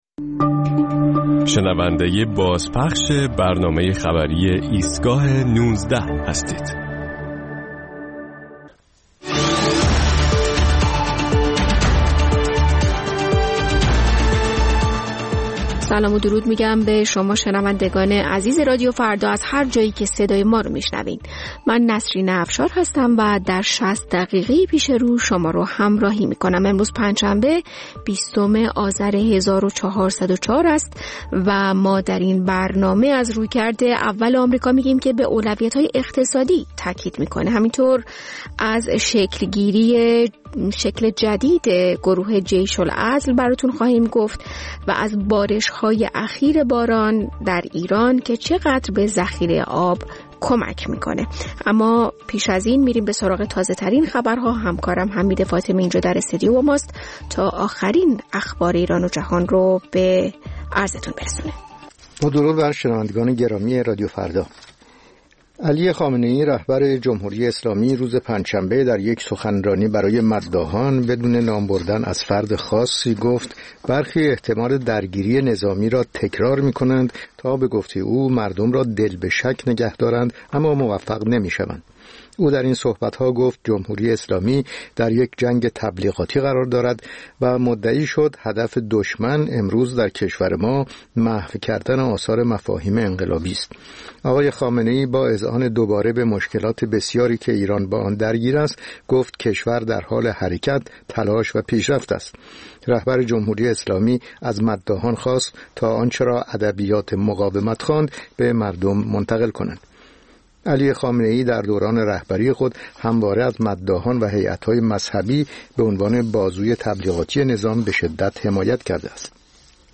مجموعه‌ای از اخبار، گزارش‌ها و گفت‌وگوها در ایستگاه ۱۹ رادیو فردا